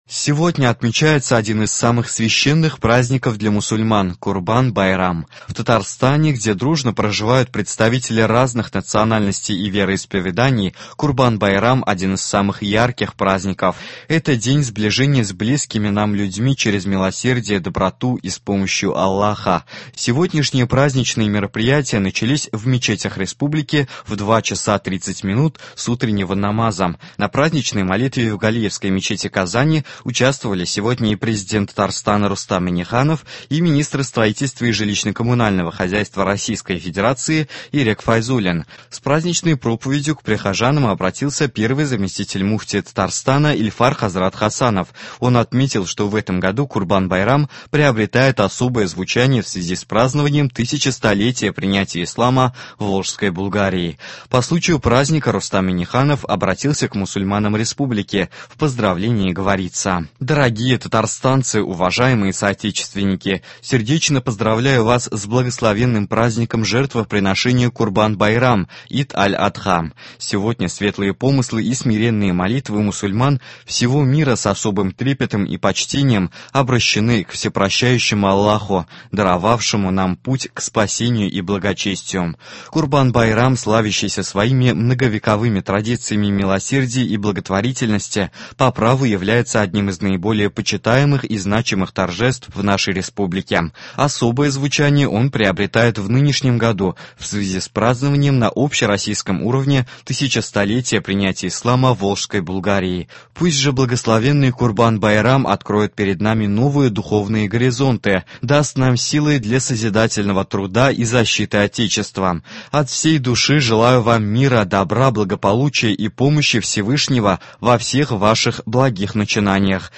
Новости (09.07.22)